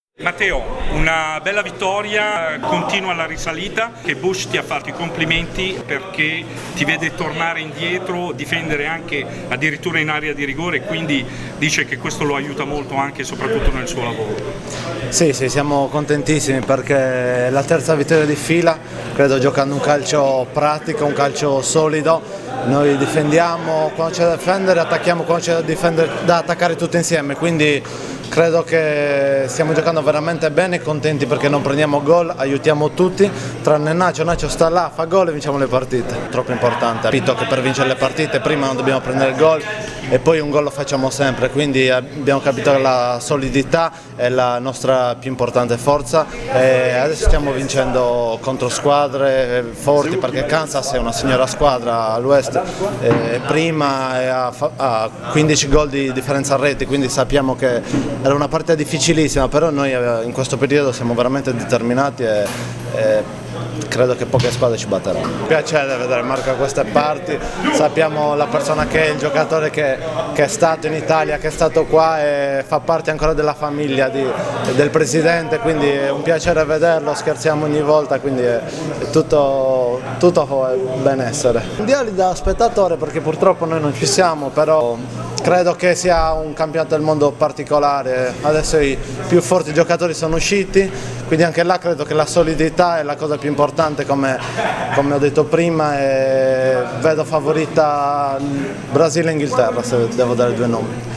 Le interviste post-partita: